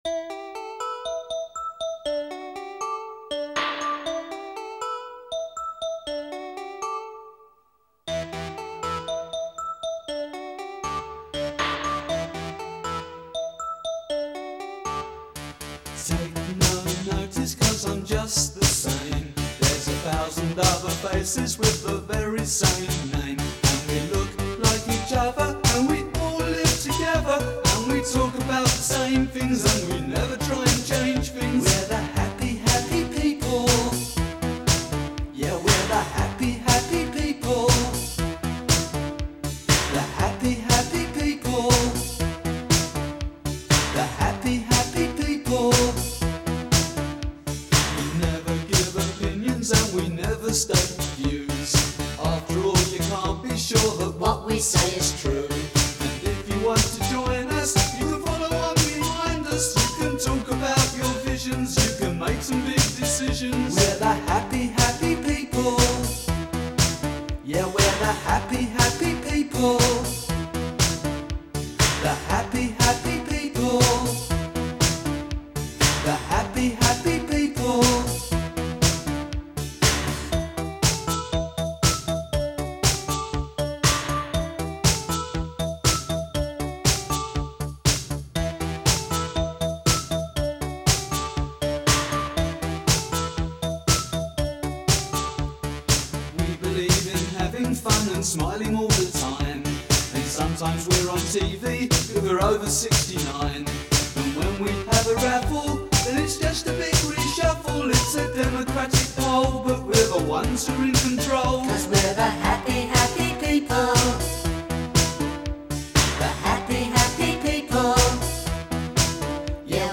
Genre : Pop, Disco